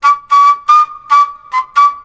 FLUT 02.AI.wav